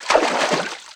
STEPS Water, Walk 13.wav